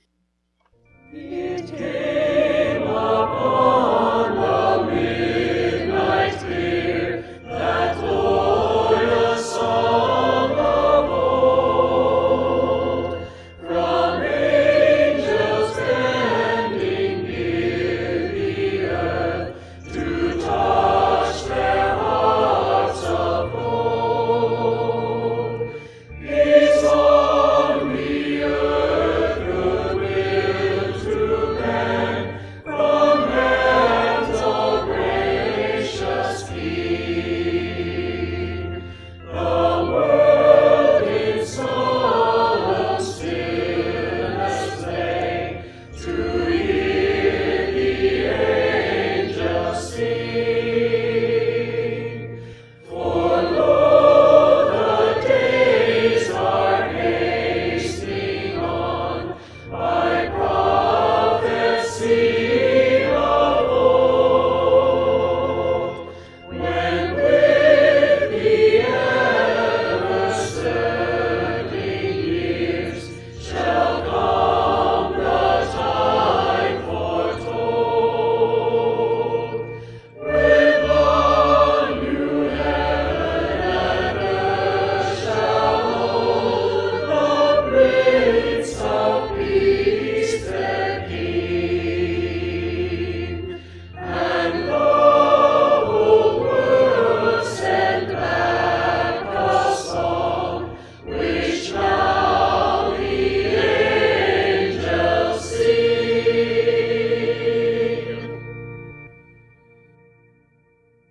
Choral music for
It Came Upon the Midnight All and Organ.mp3